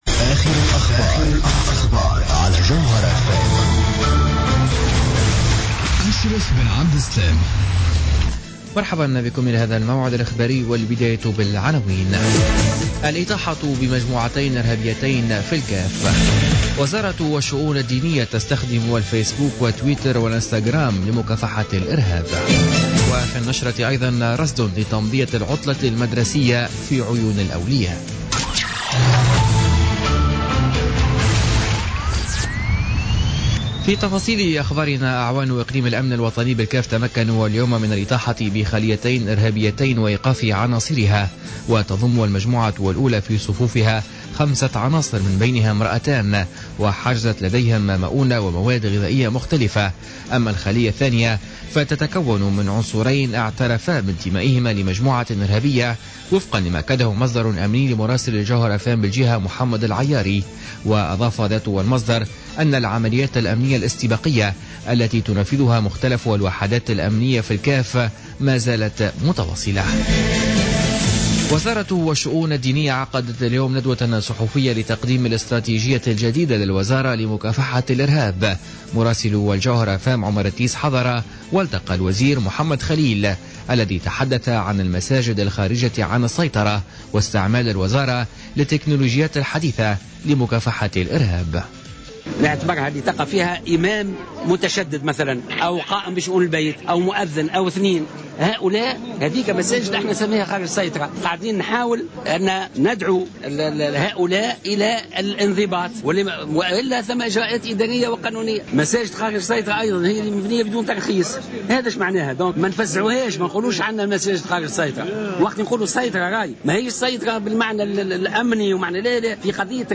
Journal Info 19h00 du jeudi 17 mars 2016